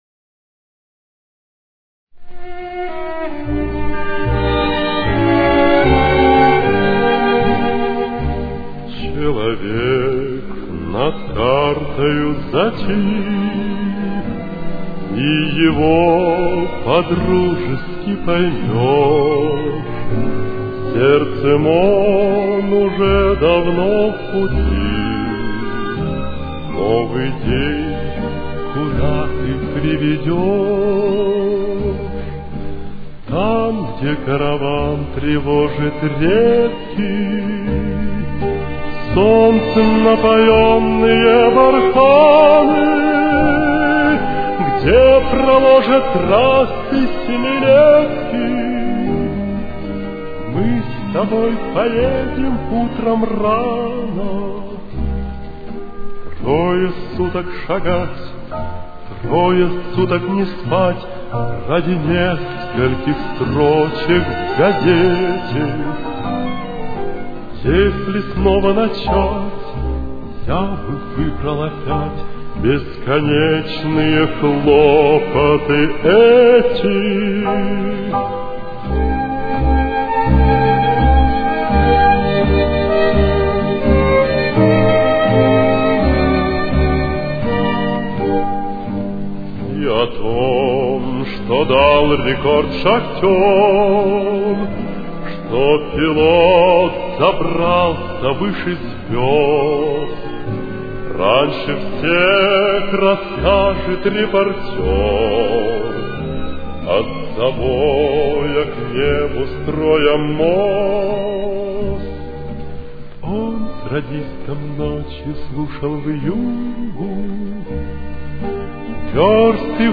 Темп: 83.